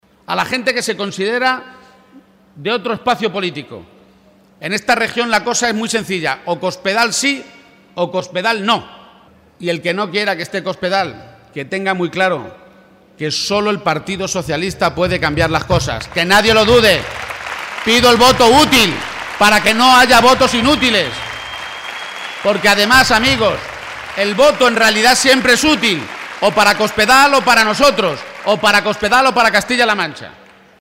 El candidato del PSOE a la Presidencia de Castilla-La Mancha, Emiliano García-Page, ha compartido esta tarde un gran acto público junto al secretario general socialista, Pedro Sánchez, en Alcázar de San Juan, ante más de 1.500 personas, y allí ha advertido una vez más que «el contador sigue en marcha, y va marcha atrás, como la región estos cuatro años, y seguimos sin conocer el programa electoral de Cospedal».